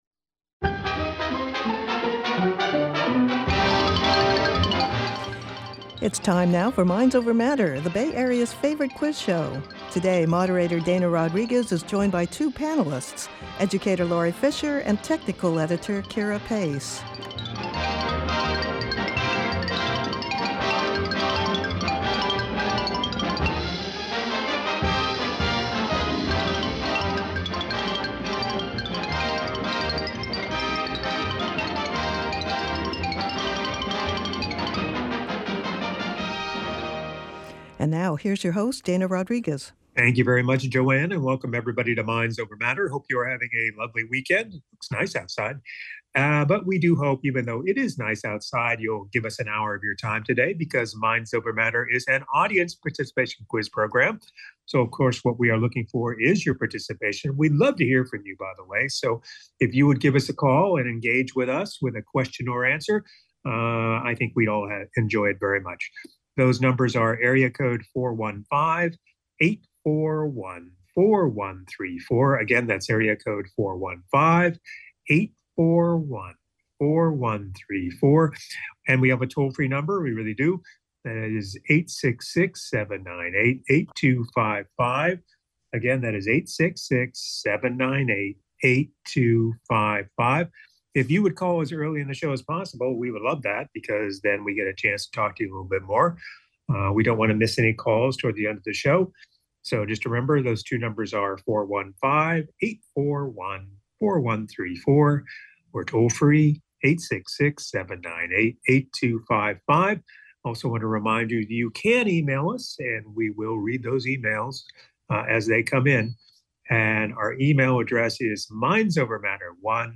The Bay Area's favorite quiz show!